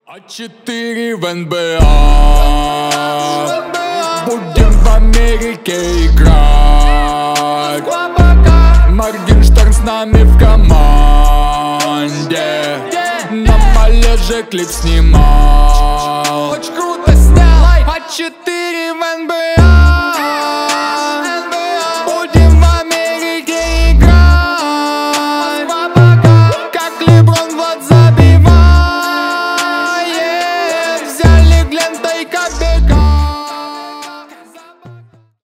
Рэп и Хип Хоп
пародия